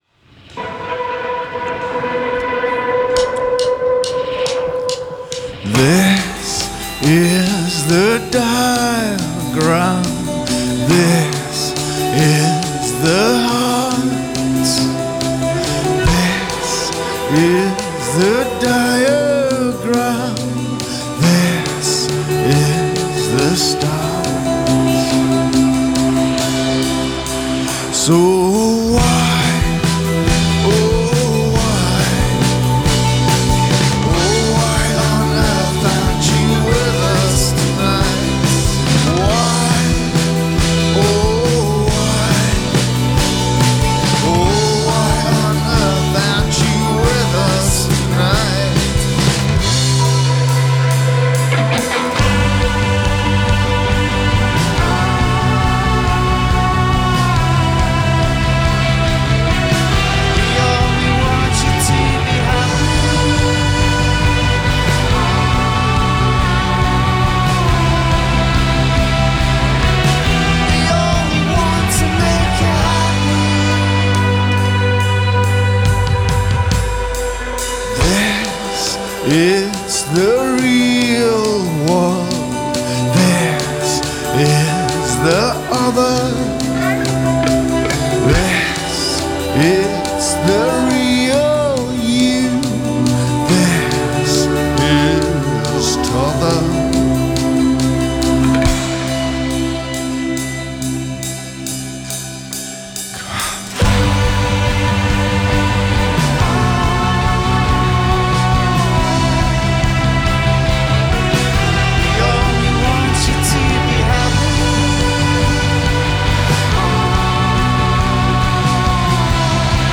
Recorded on August 24 and broadcast live.